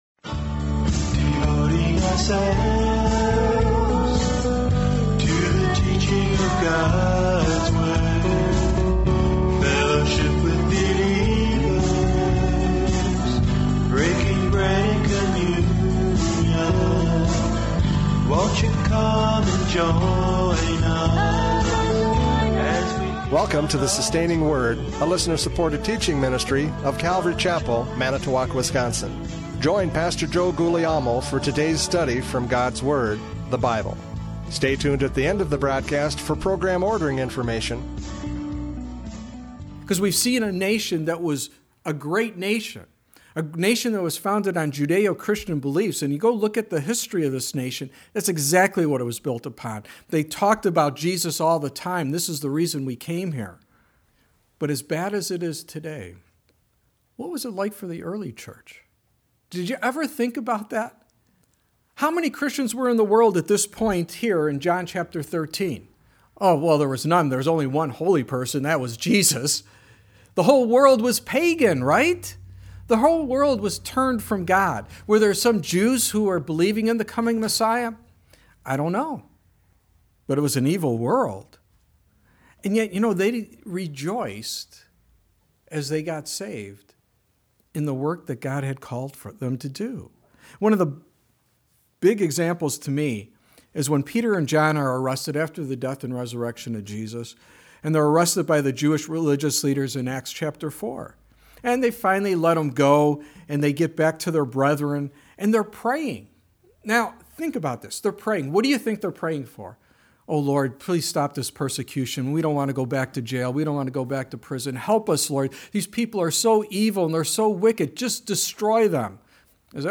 John 13:1-20 Service Type: Radio Programs « John 13:1-20 An Example of Humility!